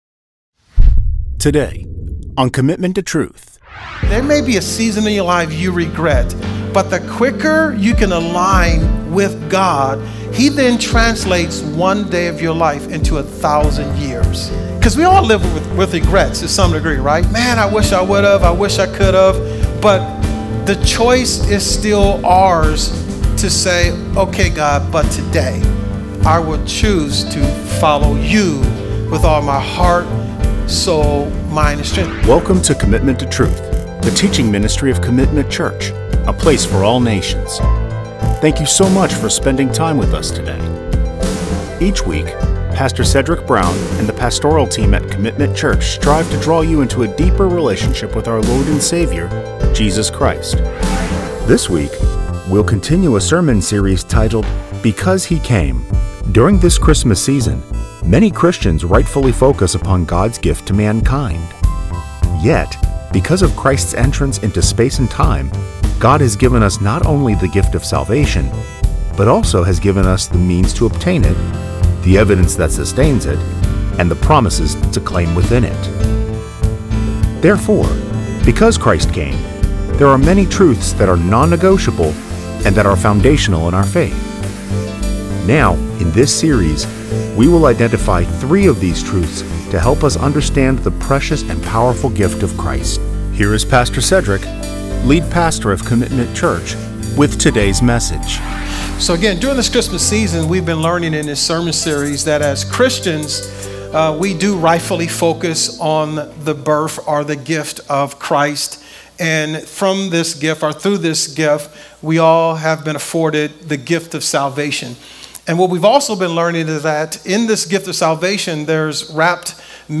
In this sermon series, our pastoral team is to remind the church that Christ’s entrance into our space and time has given us not only the gift of salvation. He has also given us the means to obtain it, the evidence that sustains it, and the promises to claim within it.